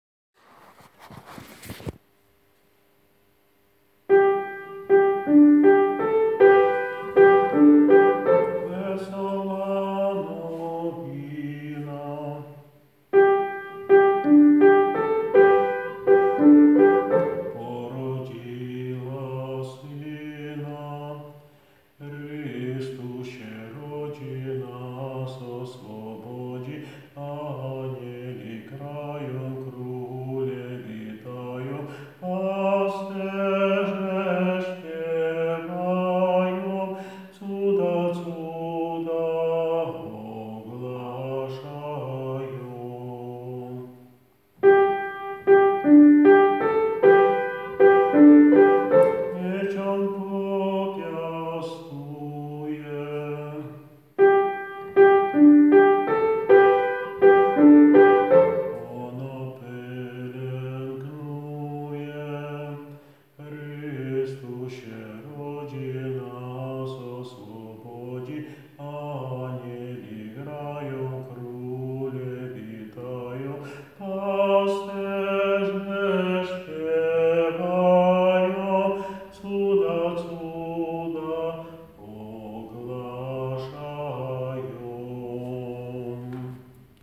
Dzisiaj w Betlejem Alt - nagranie utworu z głosem nauczyciela ze słowami a capella (bez pomocy instrumentu)